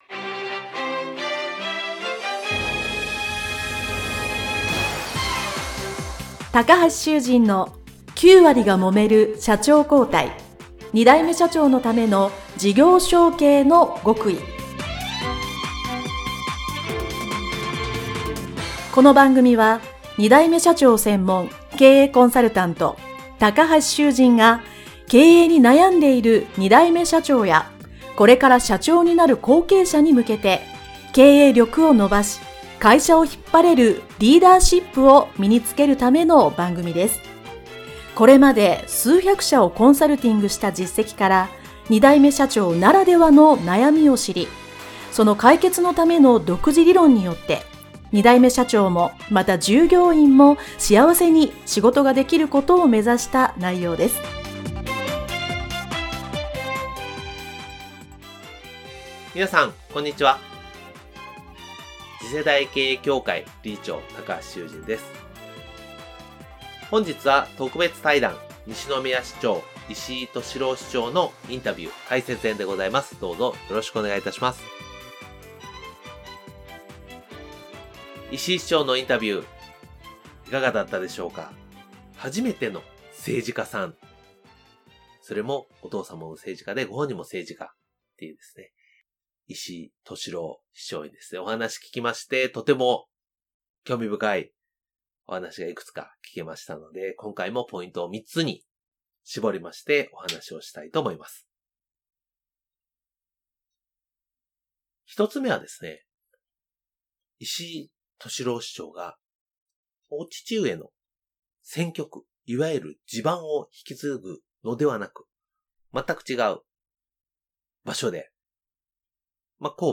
【9割がもめる社長交代】第403回＜特別対談＞西宮市長 石井としろう市長【インタビュー解説編】